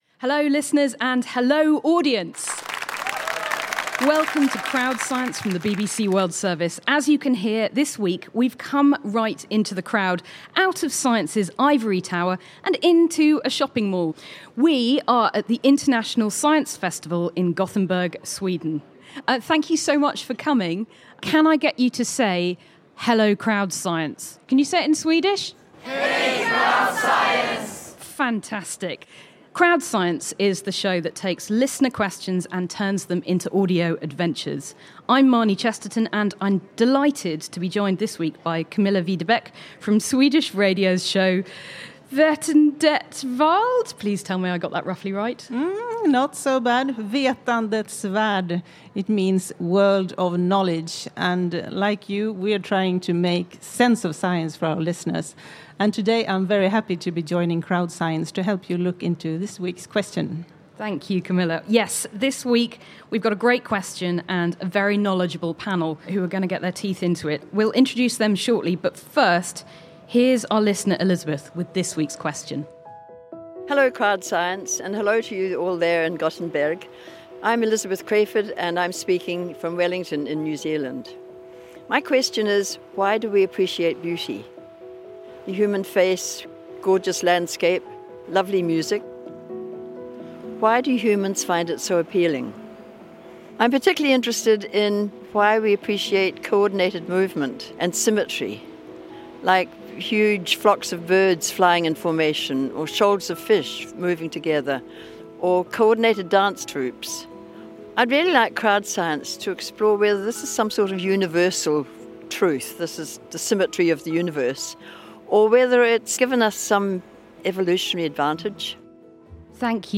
In a special edition of CrowdScience from the International Science Festival in Gothenburg, Sweden, we are joined by a panel of experts to explore how far science can explain the mystery of beauty. We look to biology, the brain, art and mathematics, to see how patterns, rhythms and symmetry contribute to our experience of beauty. And we ask whether machines can recognise or ‘appreciate’ beauty – and to what extent artificial intelligence is starting to confuse or influence what we think of as beautiful.